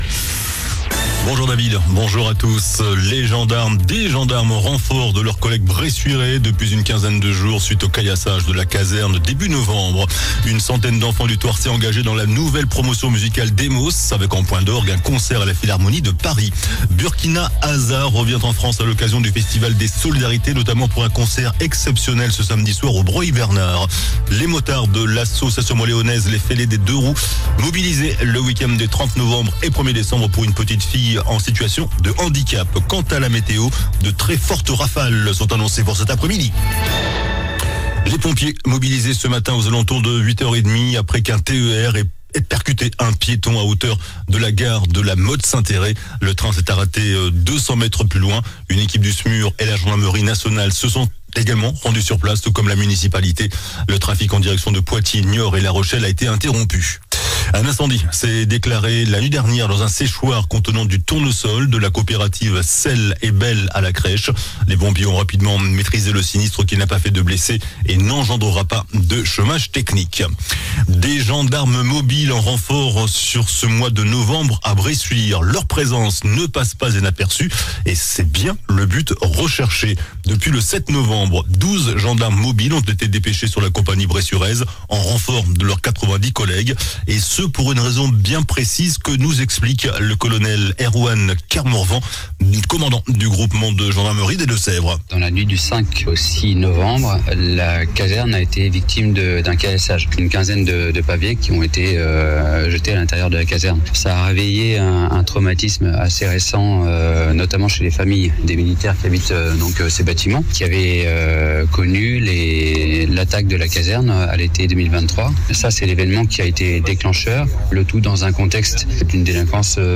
JOURNAL DU JEUDI 21 NOVEMBRE ( MIDI )